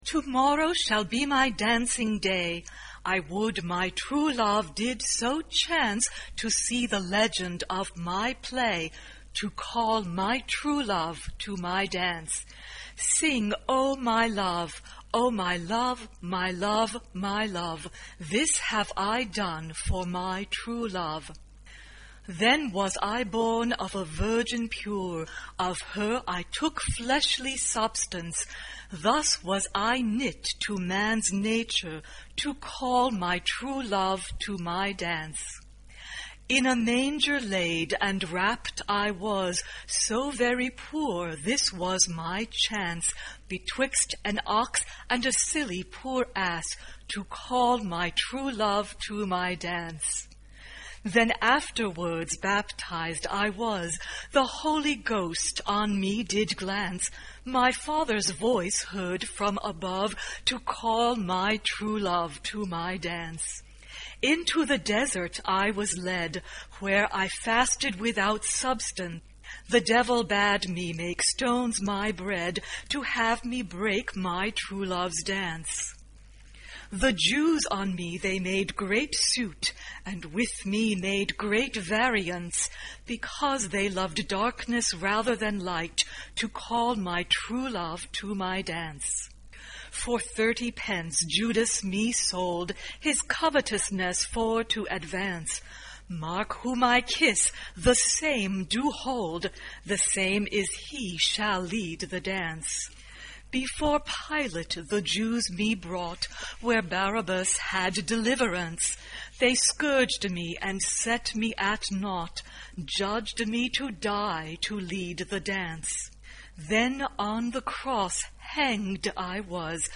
SATB (4 voix mixtes) ; Partition complète.
Motet.
Chant de Noël.
Tonalité : mi mineur